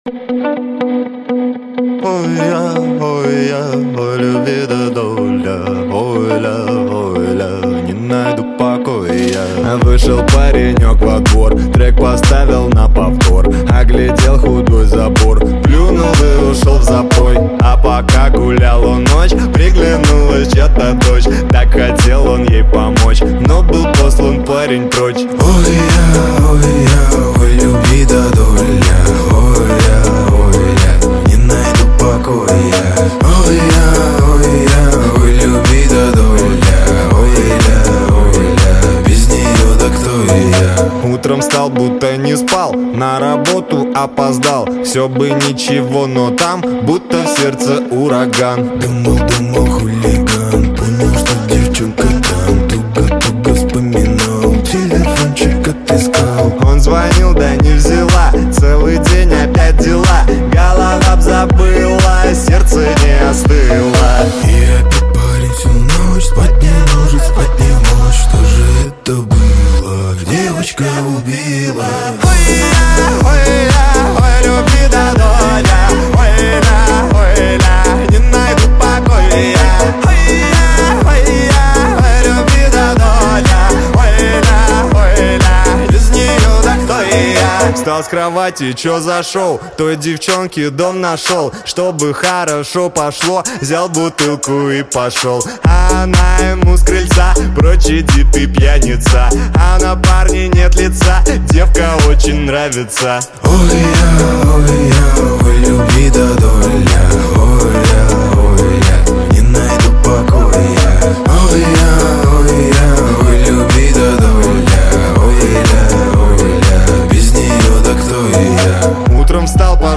CHastushki_128kbps.mp3